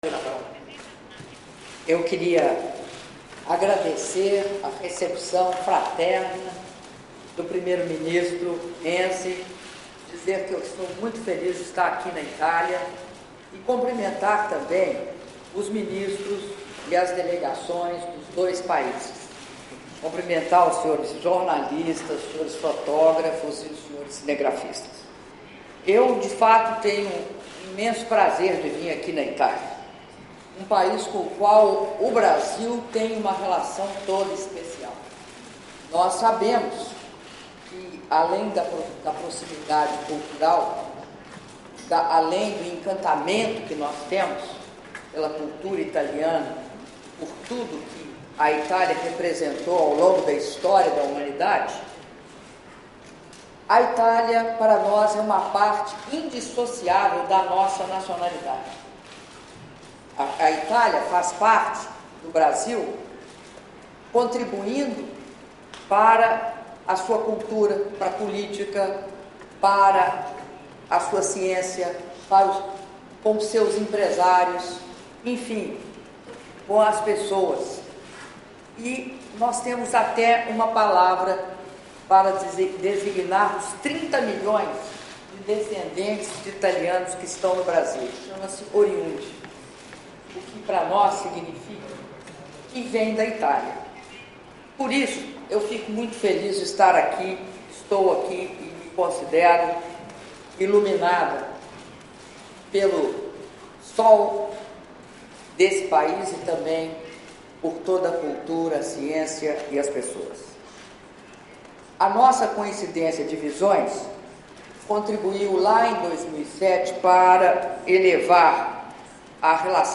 Áudio da declaração à imprensa da Presidenta da República, Dilma Rousseff, após reunião de trabalho com o Primeiro-Ministro da República Italiana, Matteo Renzi - Roma/Itália (10min19s)